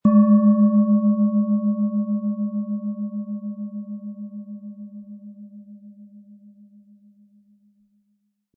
Tibetische Bauch-Kopf-Herz- und Schulter-Klangschale, Ø 12 cm, 180-260 Gramm, mit Klöppel
Im Lieferumfang enthalten ist ein Schlegel, der die Schale wohlklingend und harmonisch zum Klingen und Schwingen bringt.